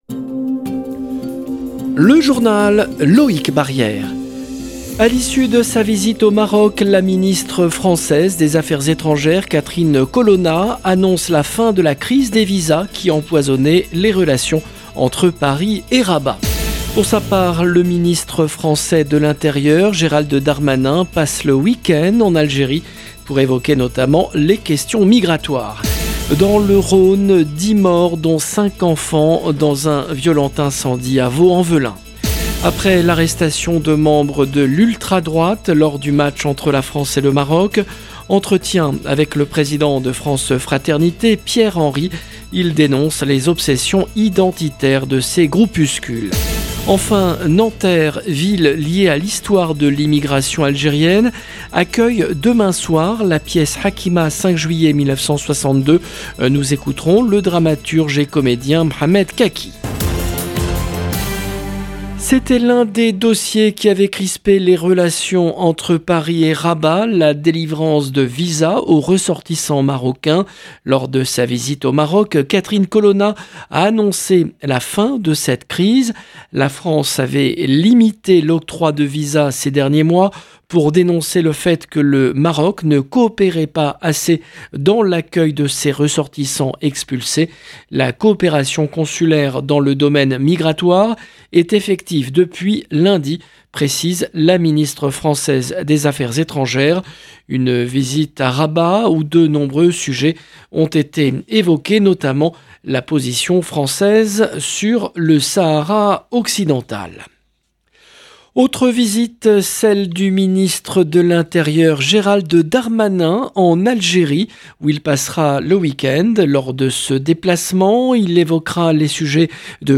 LE JOURNAL DU SOIR EN LANGUE FRANCAISE DU 16/12/22